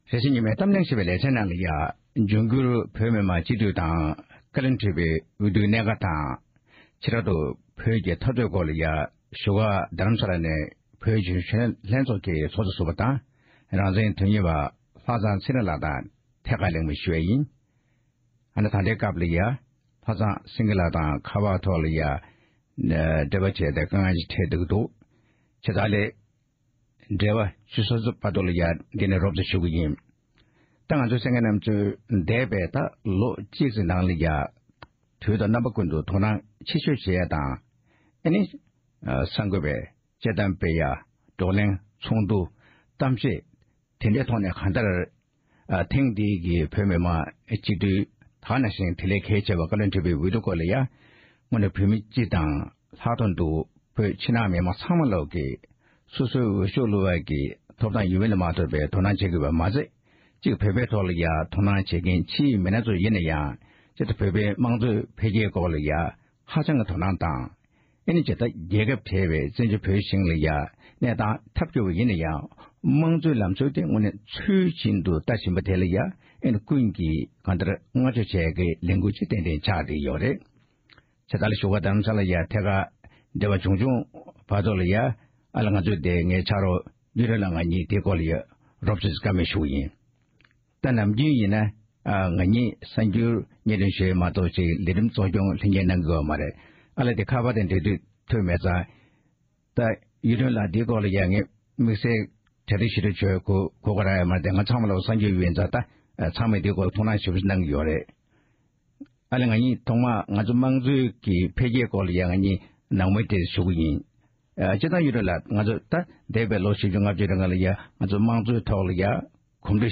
འབྱུང་འགྱུར་བོད་མི་མང་སྤྱི་འཐུས་དང་བཀའ་བློན་ཁྲི་པའི་འོས་བསྡུའི་གནད་འགག་སྐོར་གླེང་བ།